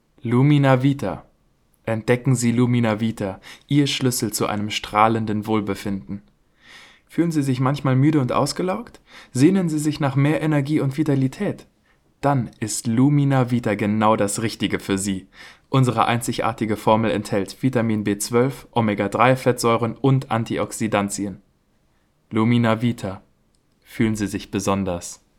Deutscher Sprecher, Helle Stimme, Junge Stimme, Mittel alte Stimme, Schauspieler, Sänger, Werbesprecher, Off-Sprecher, Dokumentation, Geschichte, Buch
Sprechprobe: Werbung (Muttersprache):